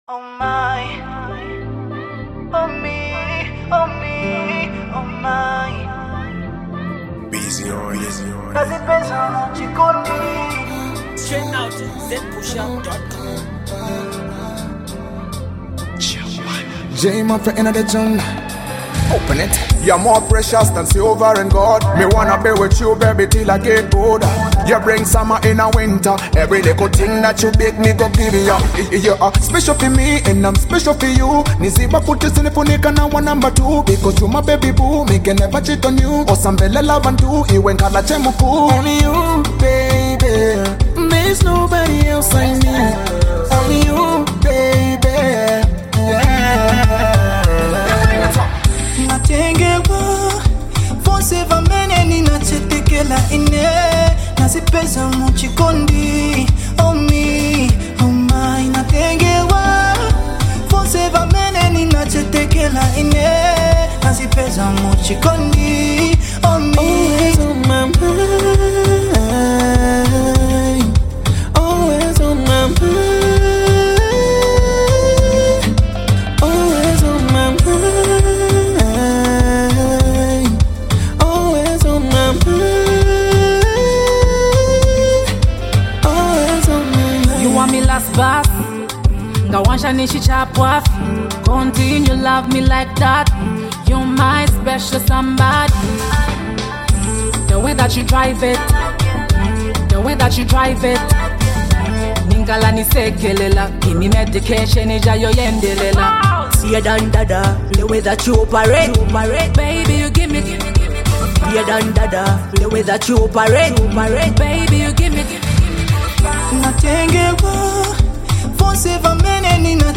dope RnB